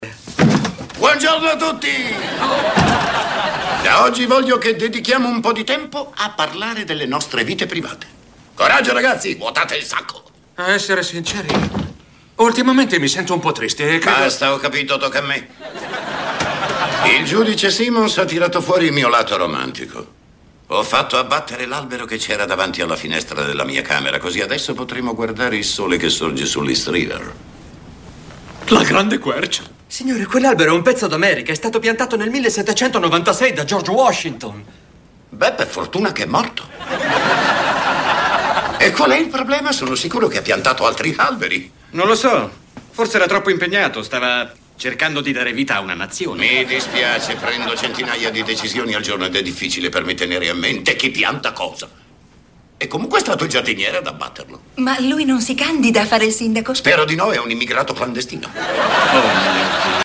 nel telefilm "Spin City", in cui doppia Barry Bostwick.